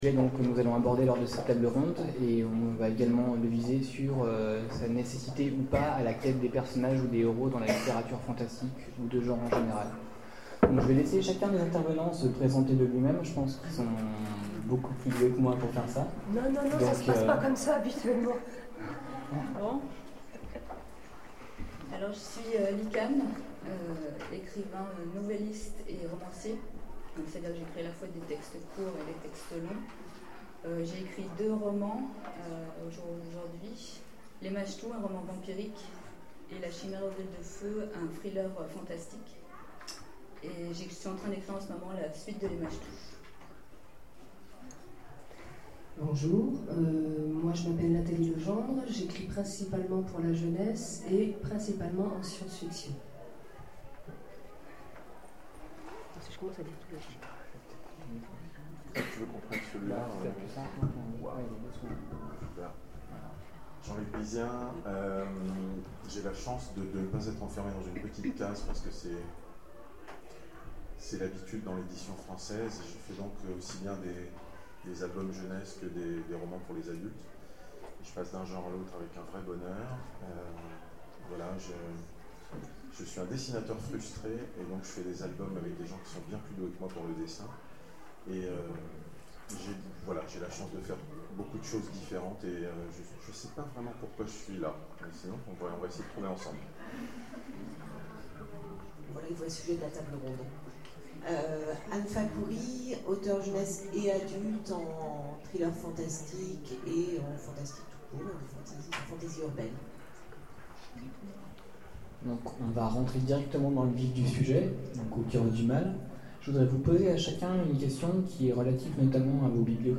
Oniriques 2013 : Conférence Le Mal élémentaire